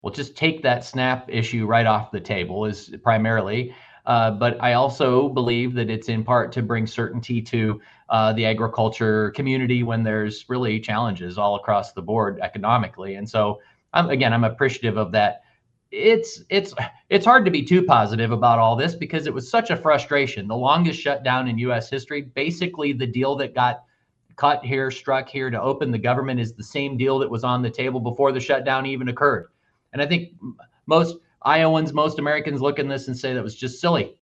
It was a major topic when I sat down for my monthly conversation with Iowa Secretary of Agriculture Mike Naig. Our interview took place the morning after the shutdown ended, and Naig said right away that securing a complete budget for USDA matters for farmers at a time when the economy is still uncertain.